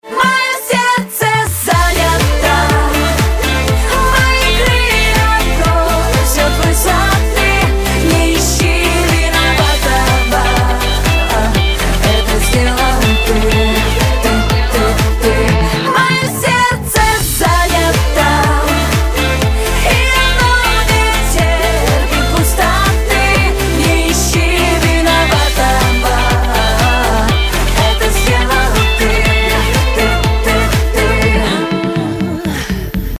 • Качество: 192, Stereo
поп
женский вокал